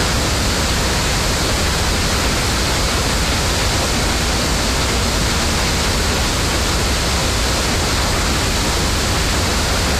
Waterfall2.ogg